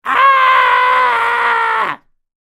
FueraDeEscala/Assets/Game Kit Controller/Sounds/Voices/Screams/Scream 1.wav at 1e752fce75d7447cdbee44b8826d64d13bf99b3a
Scream 1.wav